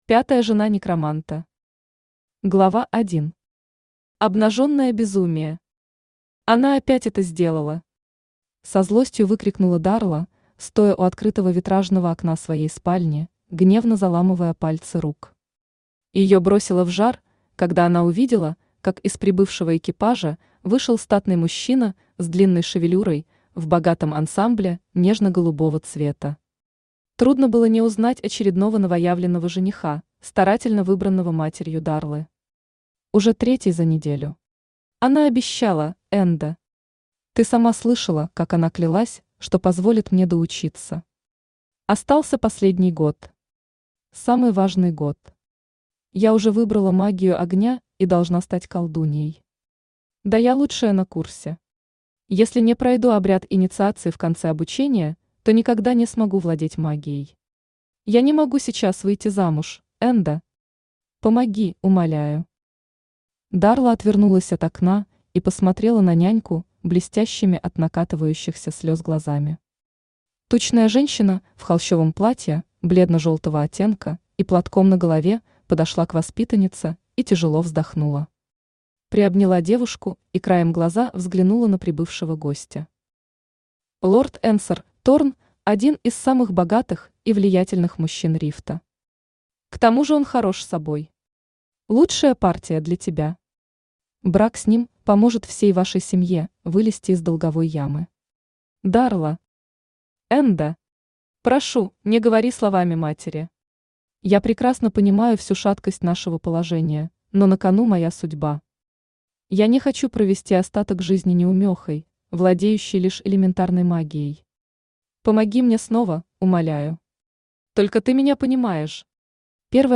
Аудиокнига Пятая жена некроманта | Библиотека аудиокниг
Aудиокнига Пятая жена некроманта Автор Юлия Пульс Читает аудиокнигу Авточтец ЛитРес.